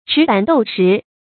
尺板斗食 注音： ㄔㄧˇ ㄅㄢˇ ㄉㄡˋ ㄕㄧˊ 讀音讀法： 意思解釋： 舊時形容小官位低祿少。